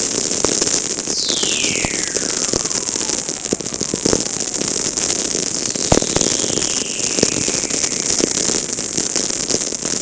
Description: A whistler and its 3-hop echo. High "spheric" activity.